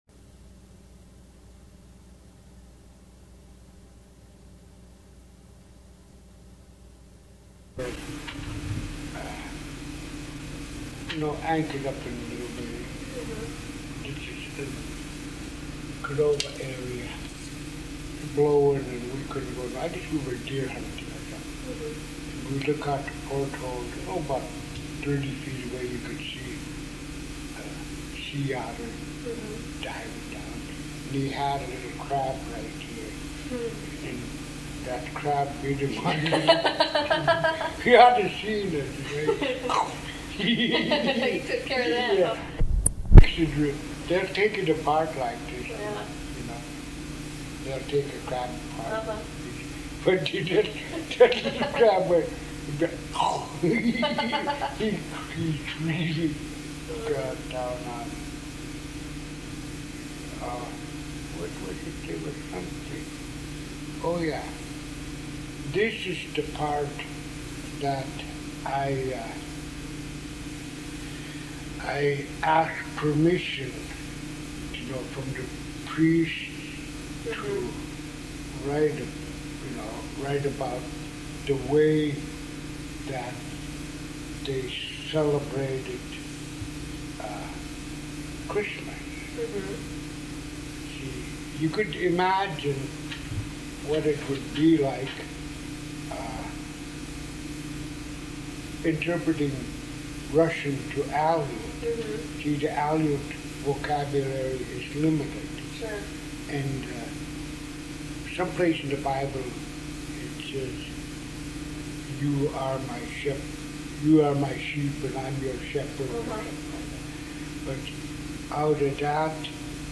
He discusses religious symbols (including sheep and stars), masquerading, and respecting the dead. Quality: poor (barely audible). (In English) Location: Location Description: Kodiak, Alaska Related Items: